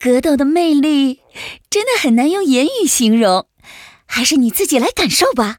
文件 文件历史 文件用途 全域文件用途 Choboong_amb_04.ogg （Ogg Vorbis声音文件，长度0.0秒，0 bps，文件大小：69 KB） 源地址:游戏语音 文件历史 点击某个日期/时间查看对应时刻的文件。